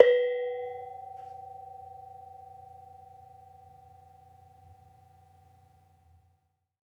Bonang-B3-f.wav